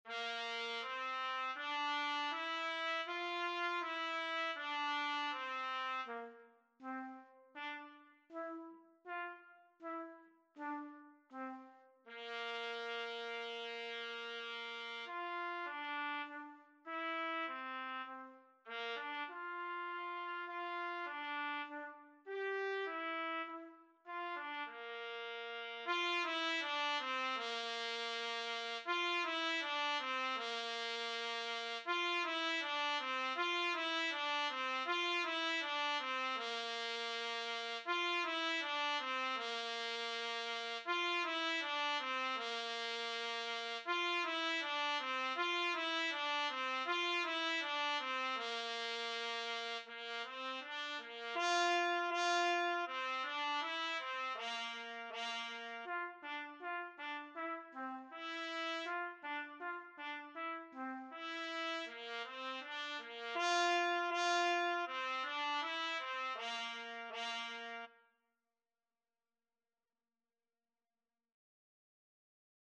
4/4 (View more 4/4 Music)
Bb4-G5
Classical (View more Classical Trumpet Music)